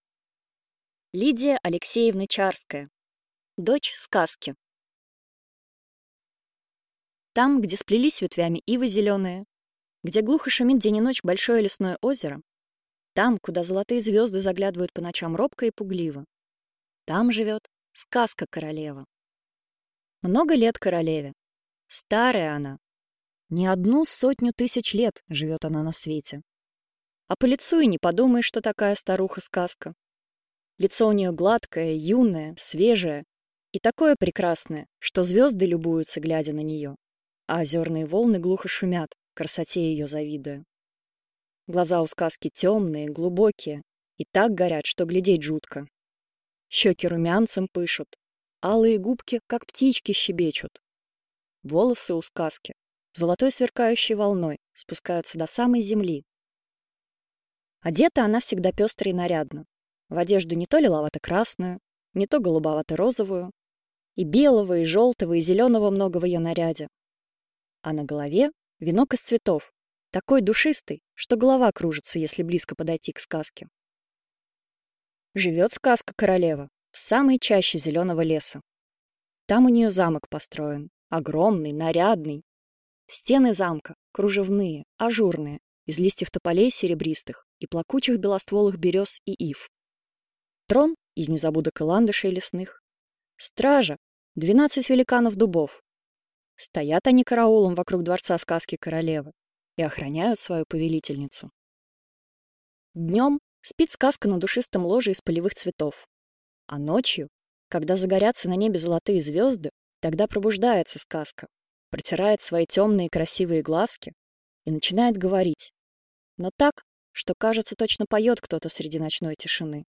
Аудиокнига Дочь Сказки | Библиотека аудиокниг